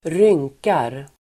Uttal: [²r'yng:kar]